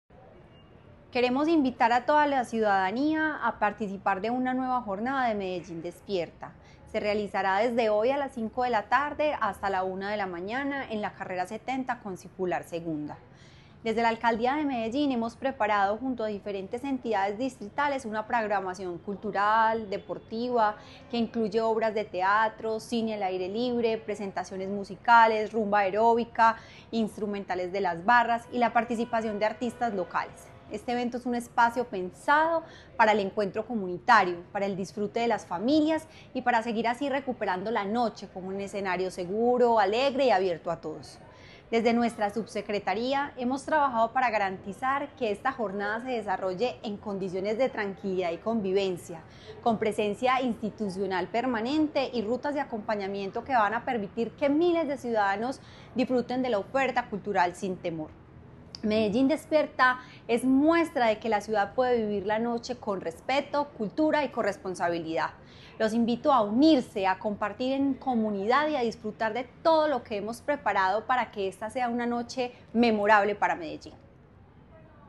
Declaraciones de la subsecretaria de Gobierno Local y Convivencia, Laura Hernández
Declaraciones-de-la-subsecretaria-de-Gobierno-Local-y-Convivencia-Laura-Hernandez.mp3